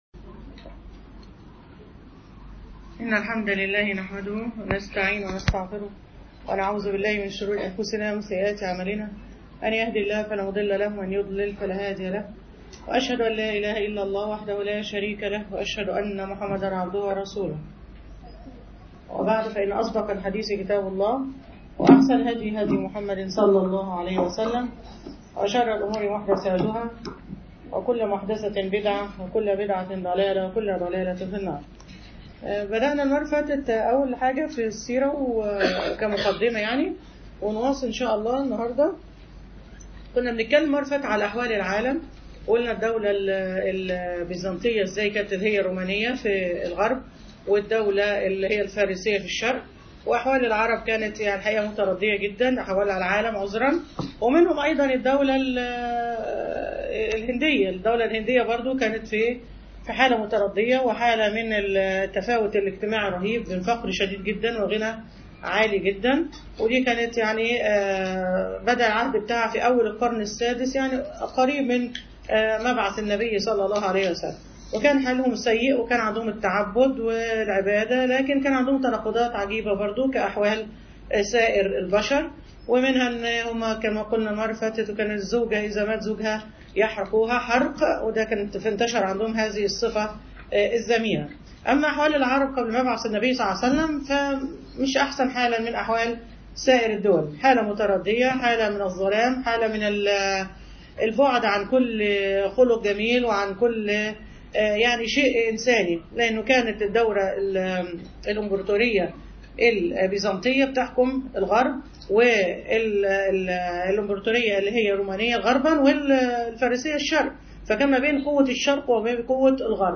سيرة النبي ﷺ: المحاضرة الثانية